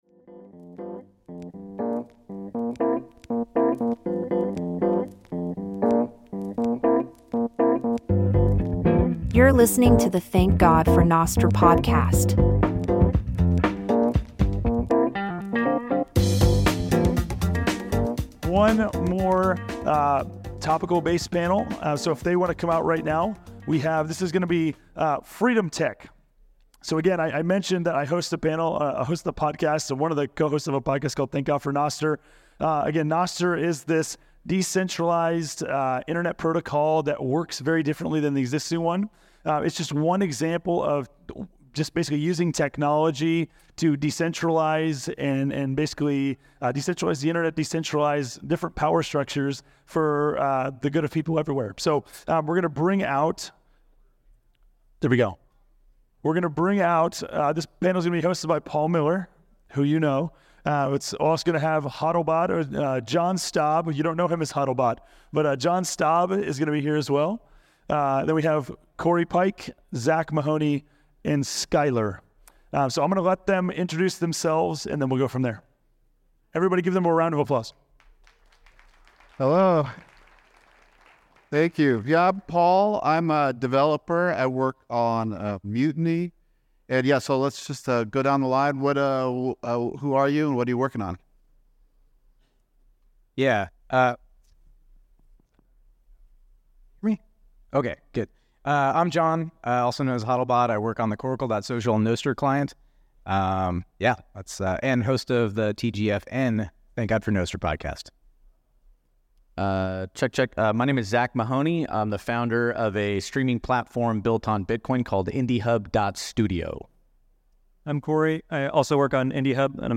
TGFB24 Panal Discussion: Freedom Tech Stack